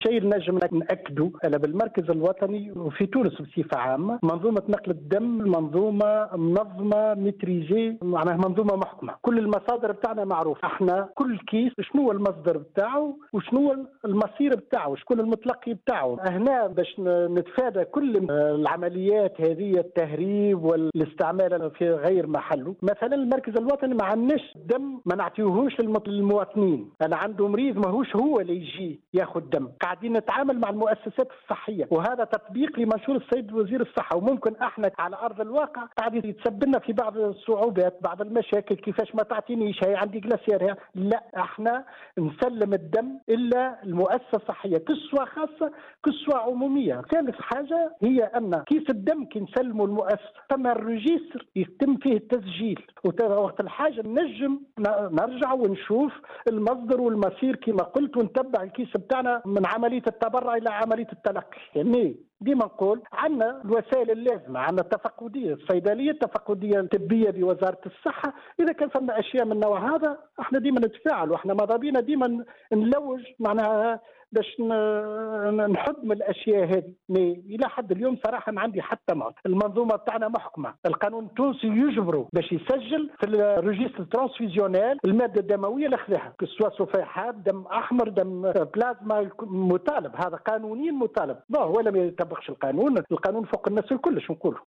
تصريح إذاعي